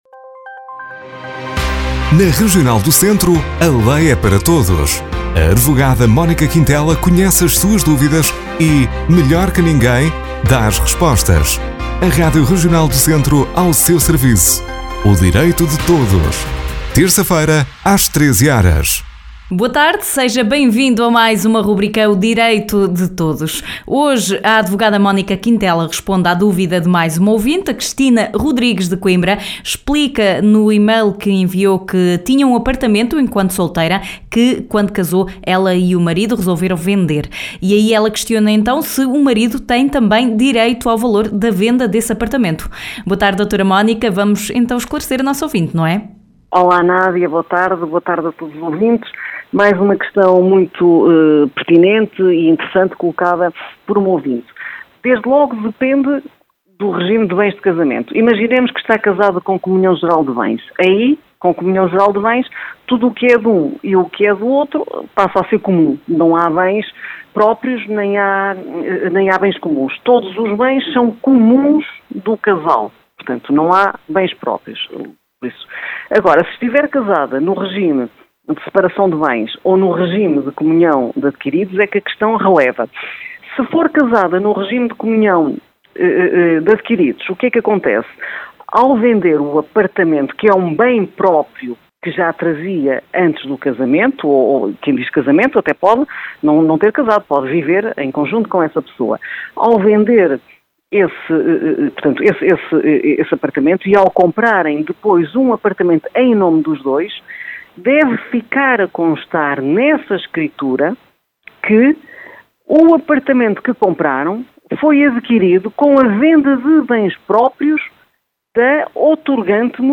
Um ouvinte questiona: “tinha um apartamento em solteira que vendi já depois de casada. O meu marido também tem direito ao valor da venda?”. A advogada Mónica Quintela responde.